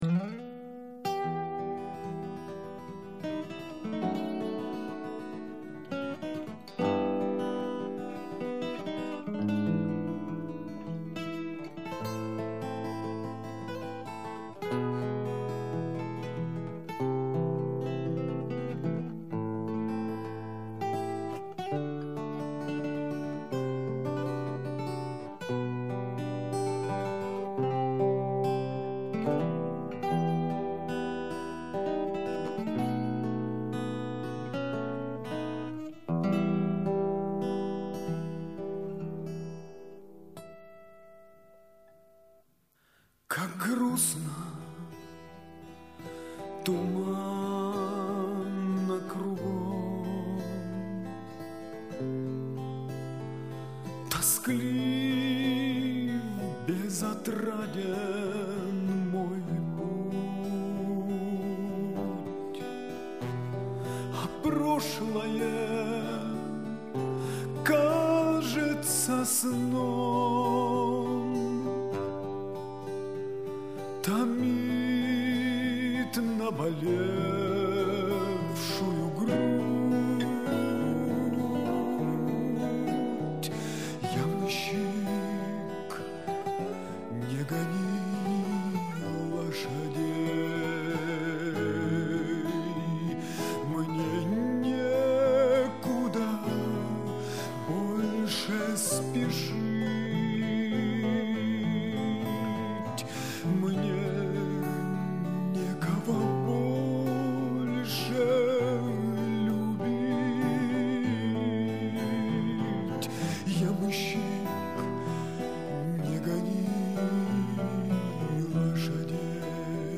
Extra : chant russe